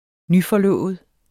Udtale [ -fʌˈlɔˀvəð ]